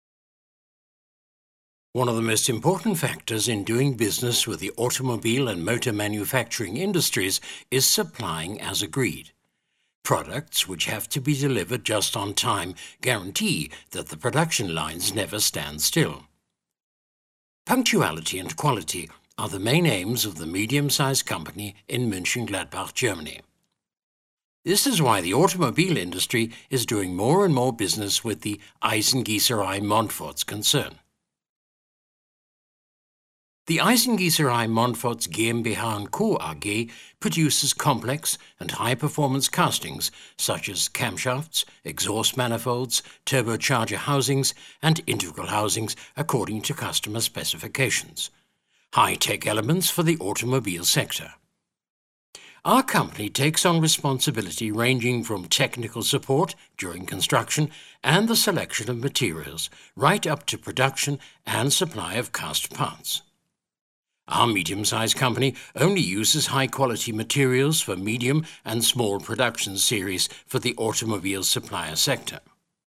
britisch
Sprechprobe: eLearning (Muttersprache):